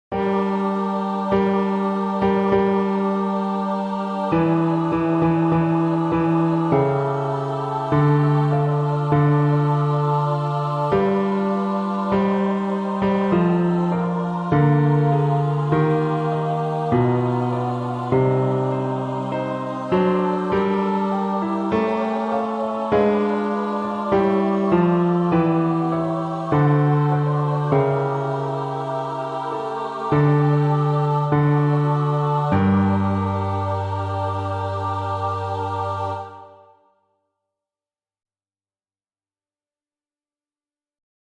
Basses
bass)Télécharger